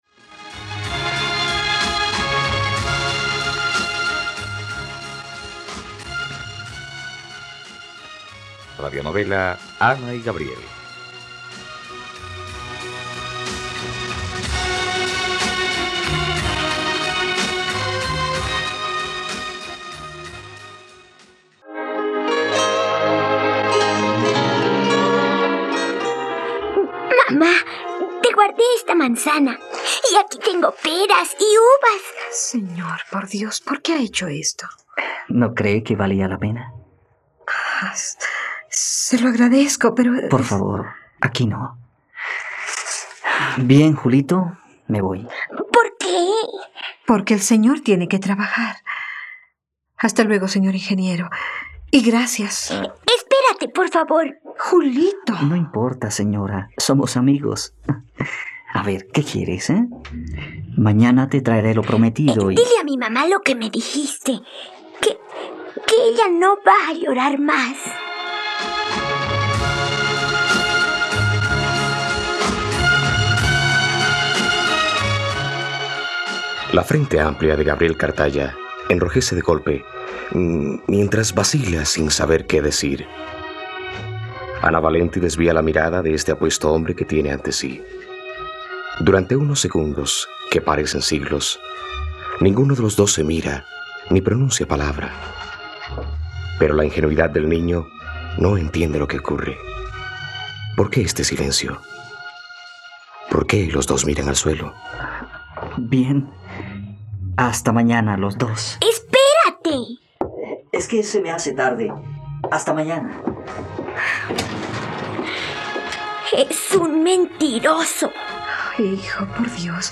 ..Radionovela. Escucha ahora el capítulo 12 de la historia de amor de Ana y Gabriel en la plataforma de streaming de los colombianos: RTVCPlay.